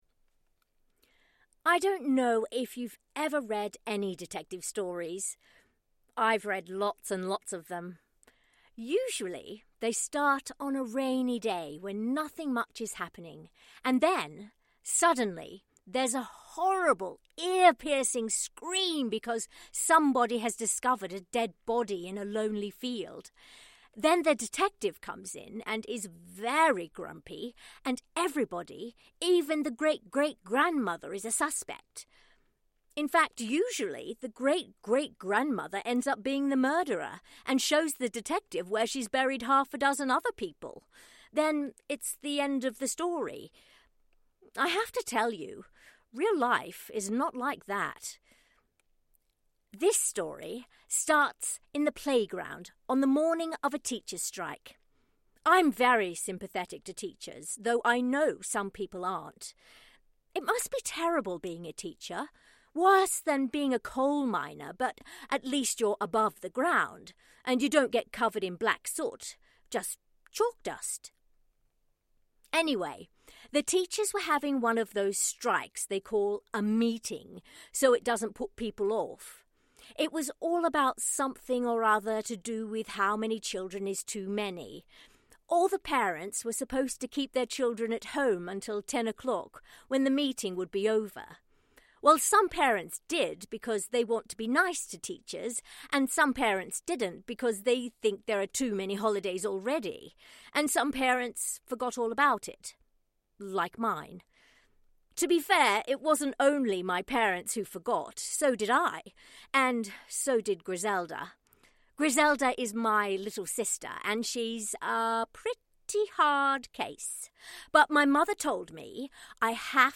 Female
English (Australian)
A bright, intelligent and natural voice with the ability to interpret nuance and complex characters.
Audiobooks
Yound Reader Fiction
Words that describe my voice are bright, Natural, sincere.